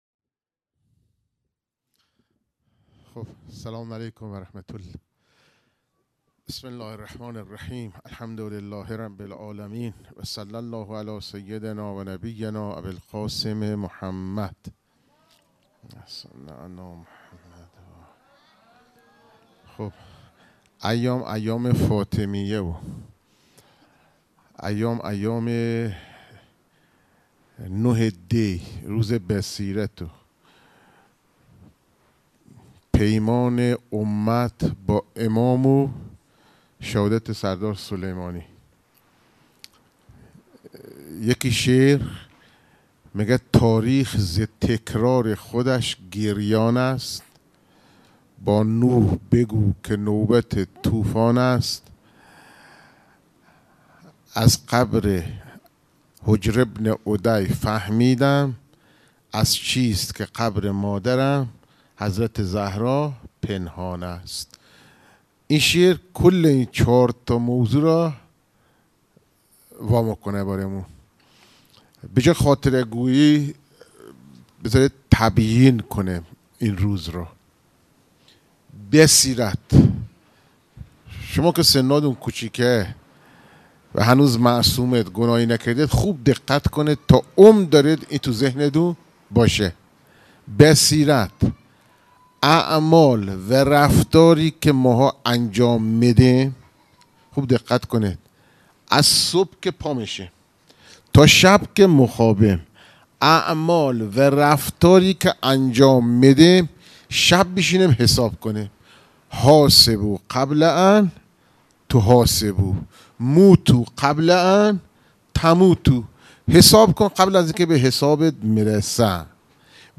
روایت گری